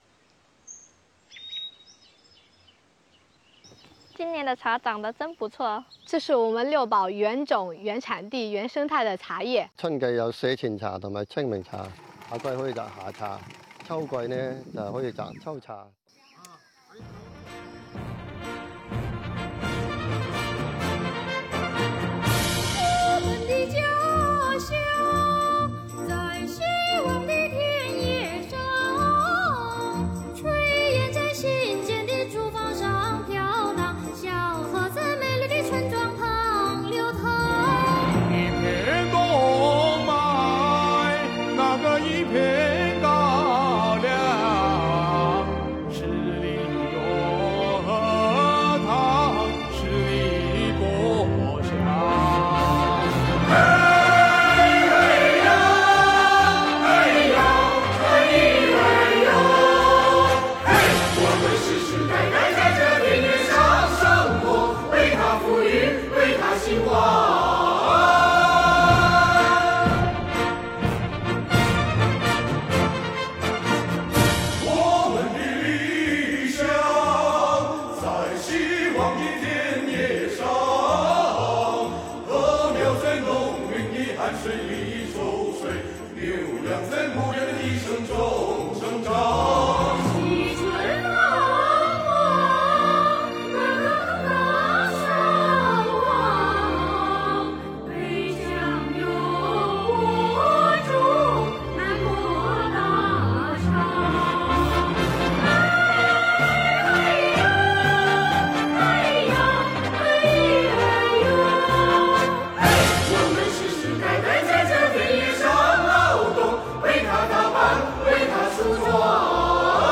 在中国共产党建党100周年来临之际，国家税务总局苍梧县税务局干部唱响红色经典歌曲《在希望的田野上》，抒发对美好生活的赞美之情，歌颂新时代、新变化、新面貌，激发全体干部爱党爱国的情怀，引导干部职工为税收现代化贡献自己的力量，向党的百年华诞致敬。